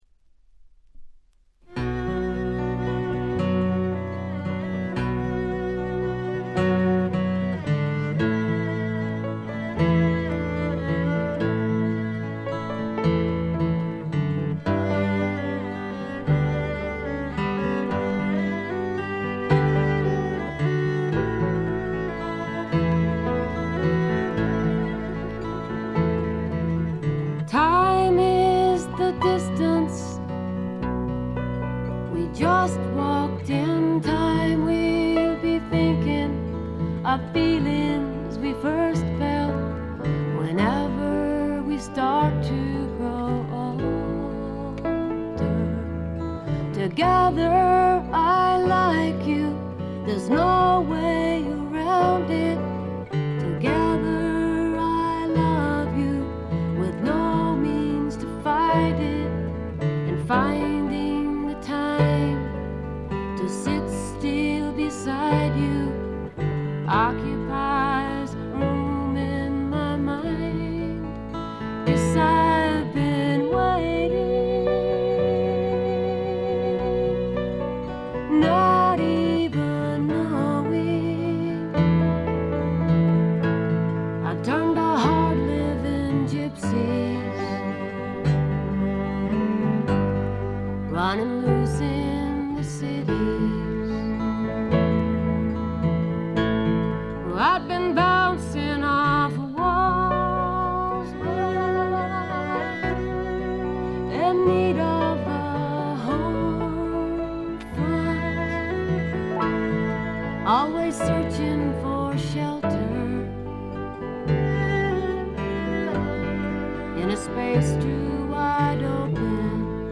軽微なチリプチ少々。
フォーキーな女性シンガーソングライター作品の大名盤です！
試聴曲は現品からの取り込み音源です。